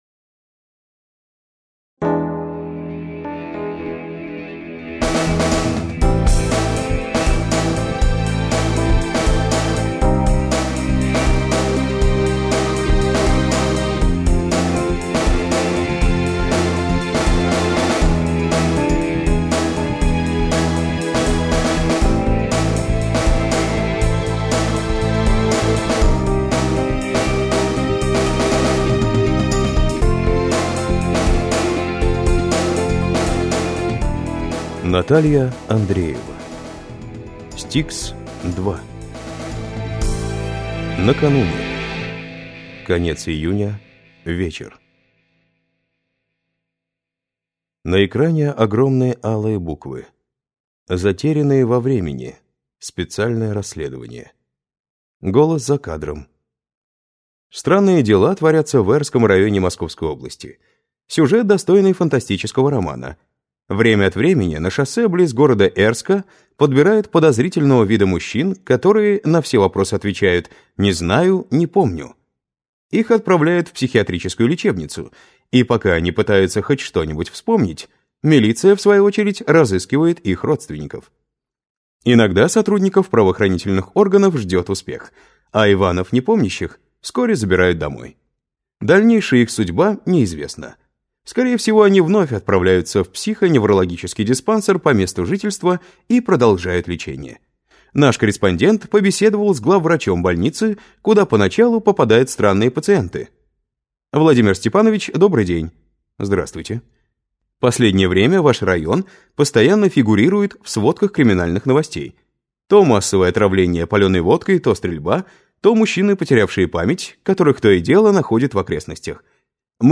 Аудиокнига Кара небесная, или Стикс-2 | Библиотека аудиокниг